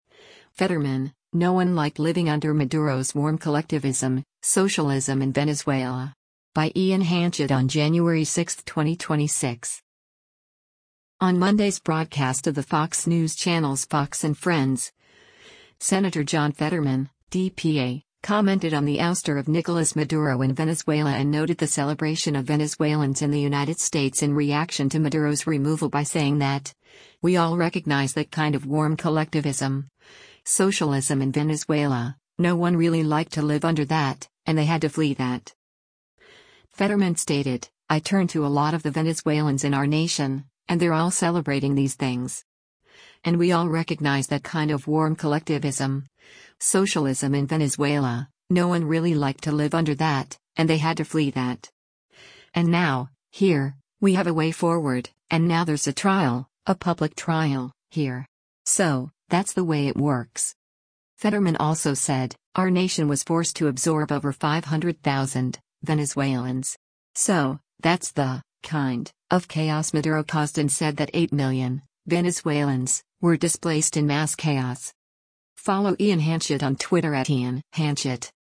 On Monday’s broadcast of the Fox News Channel’s “Fox & Friends,” Sen. John Fetterman (D-PA) commented on the ouster of Nicolas Maduro in Venezuela and noted the celebration of Venezuelans in the United States in reaction to Maduro’s removal by saying that, “we all recognize that kind of warm collectivism, socialism in Venezuela, no one really liked to live under that, and they had to flee that.”